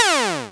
fall3.wav